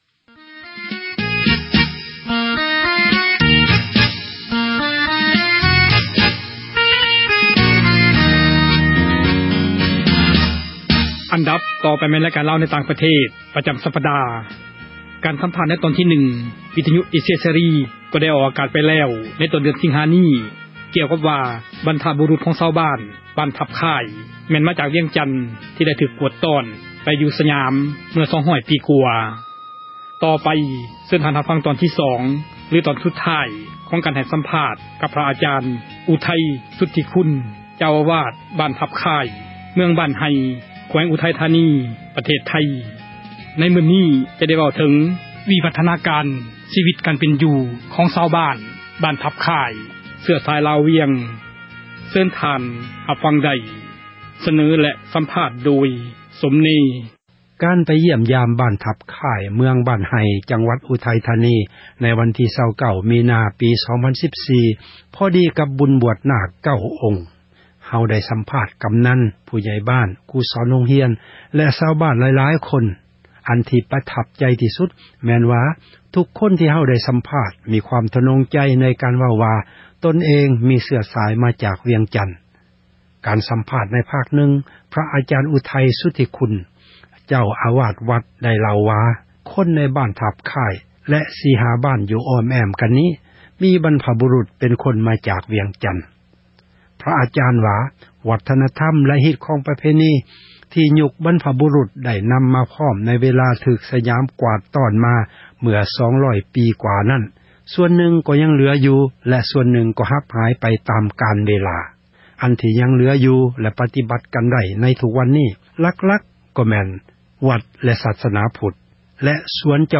ການສຳພາດ ໃນ ຕອນທີ່ 1 ວິທຍຸ ເອເຊັຽເສຣີ ກໍໄດ້ອອກ ອາກາດ ໄປແລ້ວ ໃນຕົ້ນ ເດືອນ ສິງຫາ ນີ້ ກ່ຽວກັບວ່າ ບັນພະບຸຣຸດ ຂອງ ຊາວບ້ານ ”ບ້ານທັບຄ່າຍ” ແມ່ນມາຈາກ ”ວຽງຈັນ” ທີ່ ໄດ້ຖືກ ກວາດຕ້ອນ ໄປຢູ່ສຍາມ ເມື່ອ 200 ປີ ກວ່າ ...